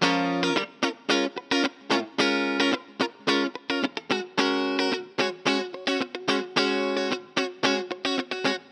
03 Guitar PT3.wav